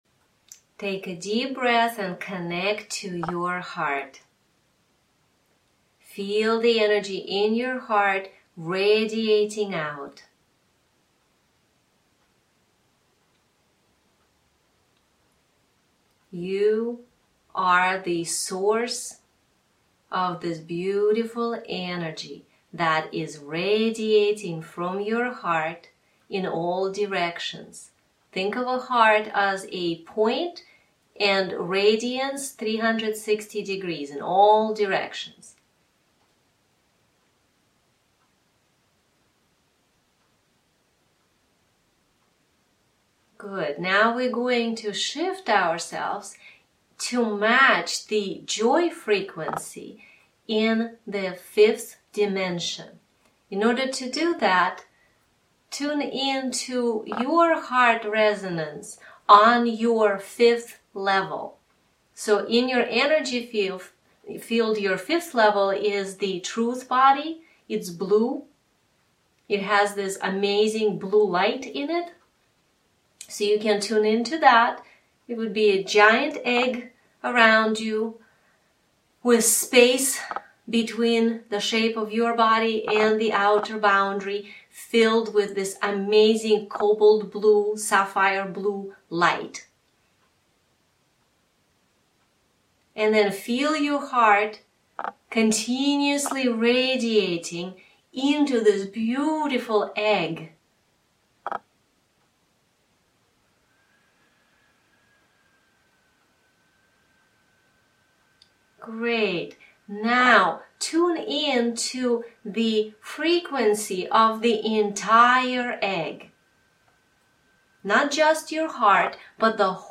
TGM_Gratitude-Joy_Meditation.mp3